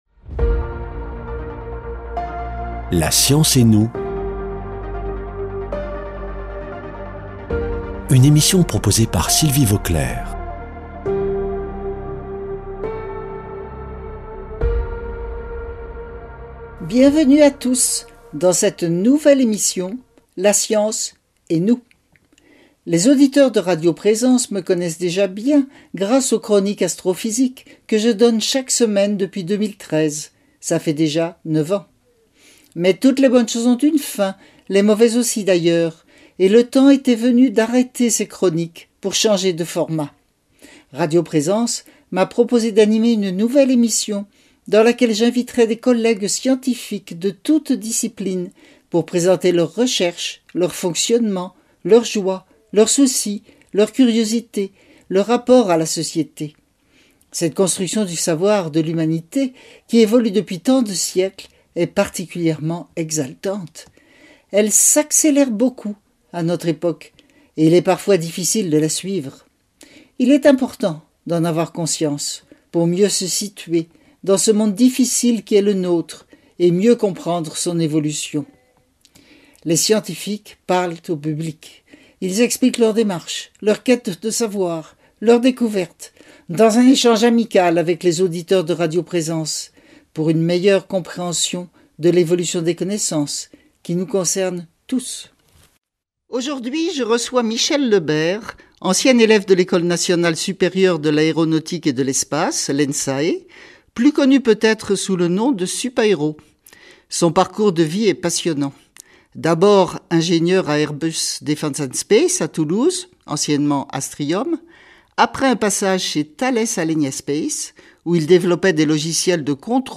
[ Rediffusion ] La mise en poste et le suivi de satellites, quels enjeux ?